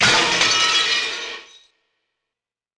Glassbreak.mp3